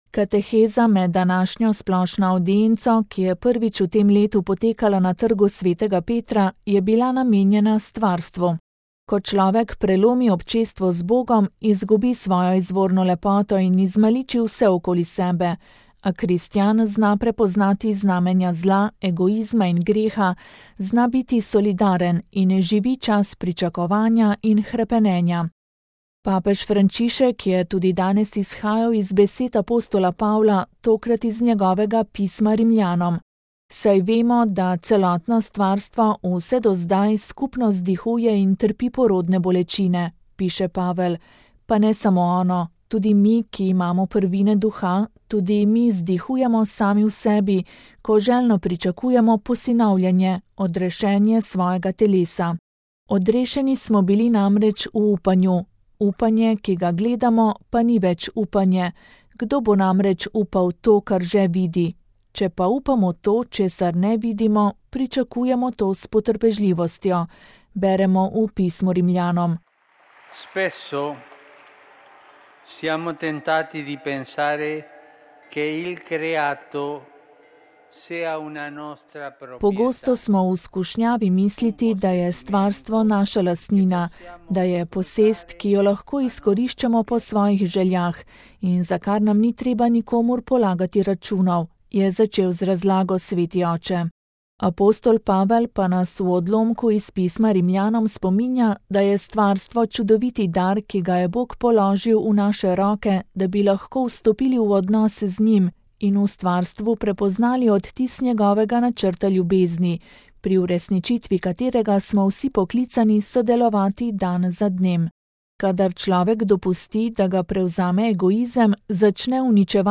Kateheza: Vse zdihuje - stvarstvo, ljudje, Duh v človeku
VATIKAN (sreda, 22. februar 2017, RV) – Kateheza med današnjo splošno avdienco, ki je prvič v tem letu potekala na Trgu svetega Petra, je bila namenjena stvarstvu. Ko človek prelomi občestvo z Bogom, izgubi svojo izvorno lepoto in izmaliči vse okoli sebe.